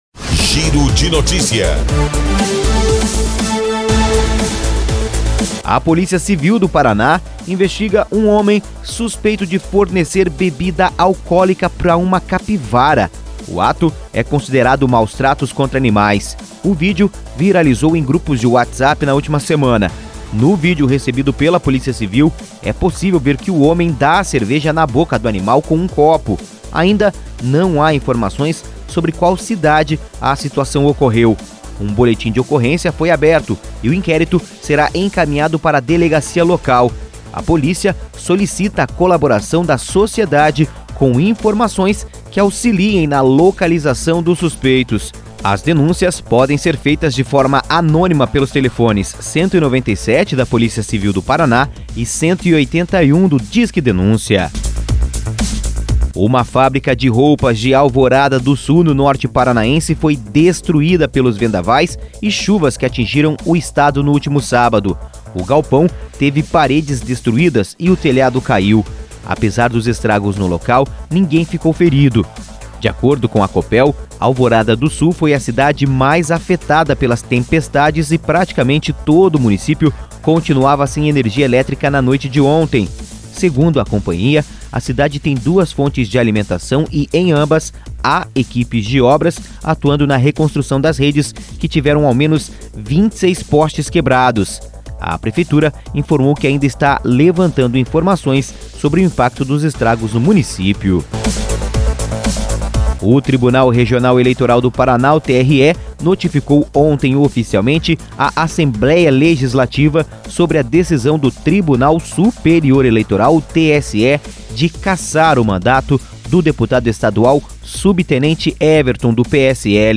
Por Jornalismo.